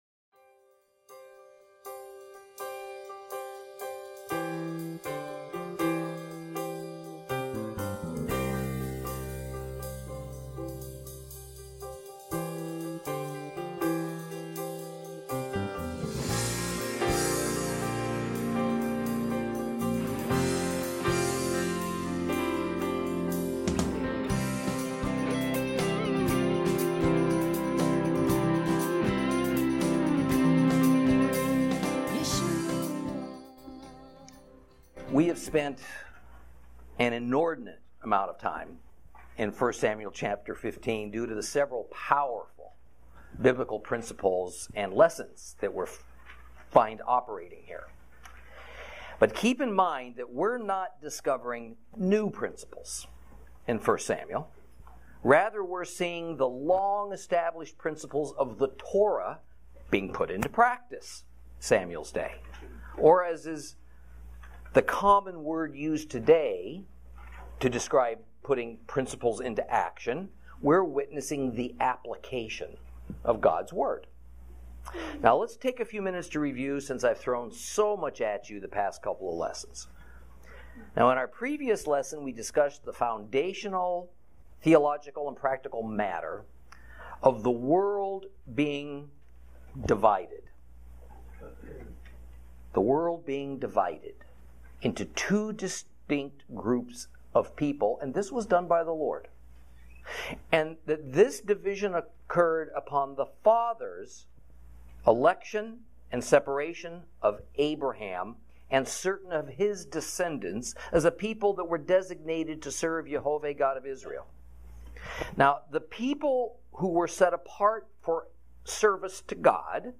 Lesson 25 Ch15 - Torah Class